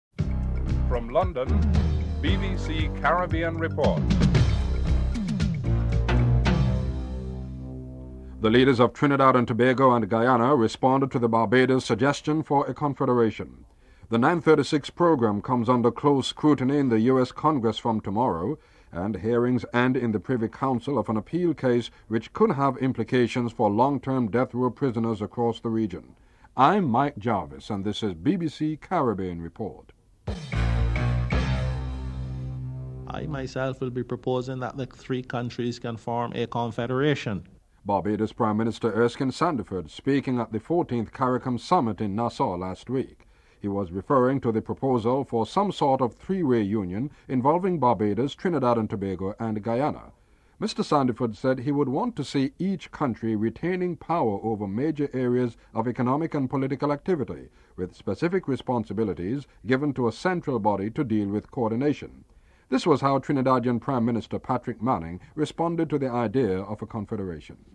1. Headlines (00:00-00:40)
Speaker, Erskine Sandiford, Prime Minister of Barbados. Interviews with Patrick Manning, Prime Minister of Trinidad and Tobago and Cheddi Jagan, President of Guyana.